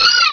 Cri de Skitty dans Pokémon Rubis et Saphir.